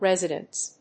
/ˈrɛzɪdʌnts(米国英語), ˈrezɪdʌnts(英国英語)/